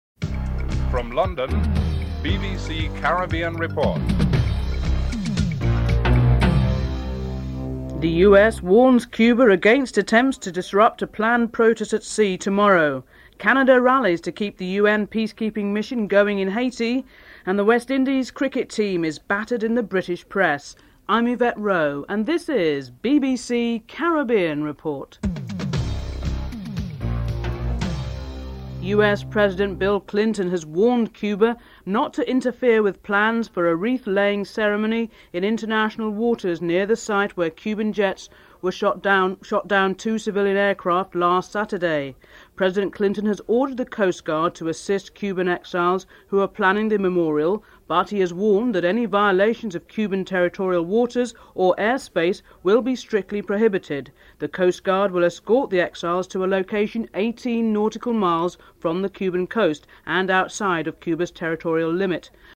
1. Headlines (00:00-00:28)
7. The West Indies cricket team is battered in the British press after being humiliated by Kenya. Former West Indies Cricketer Conrad Hunte and Kenya Captain Maurice Odumbe are interviewed (09:40-15:21)